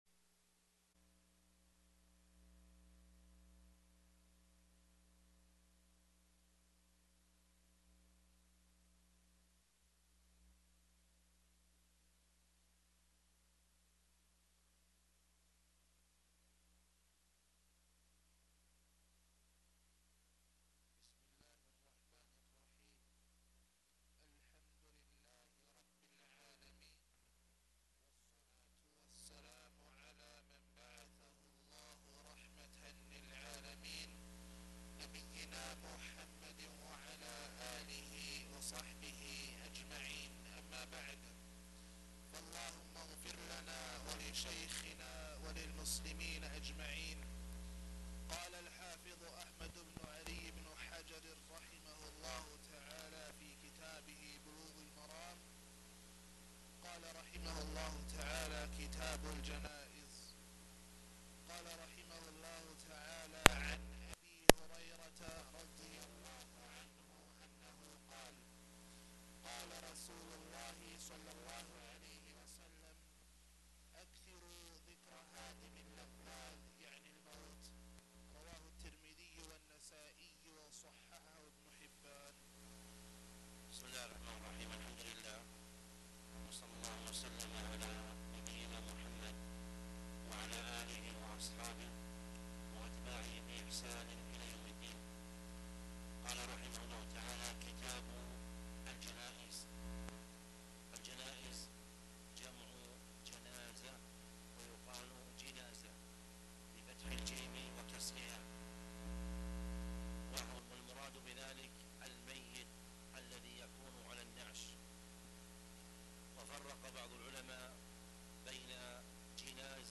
تاريخ النشر ٢٨ جمادى الأولى ١٤٣٩ هـ المكان: المسجد الحرام الشيخ